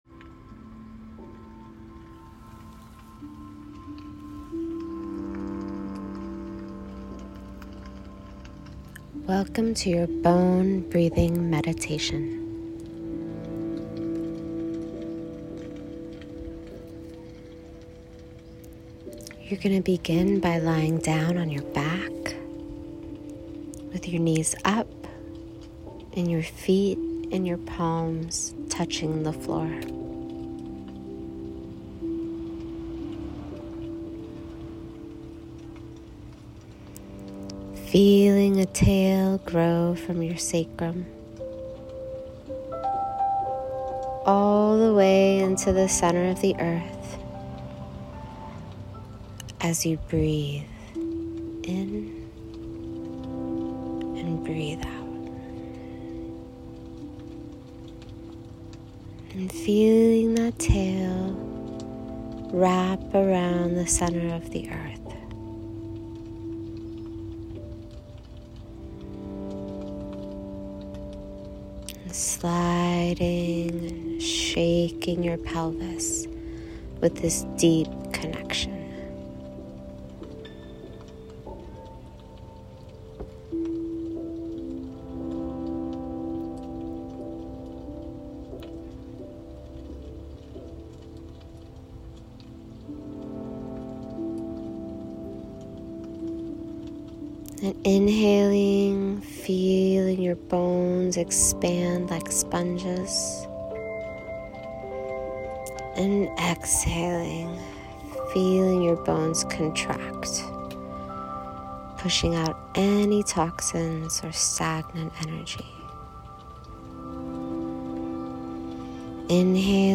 A Grounding Meditation my gift to you love Drop your name and email address below to receive your free guided practices.
bone_breathing_meditation.m4a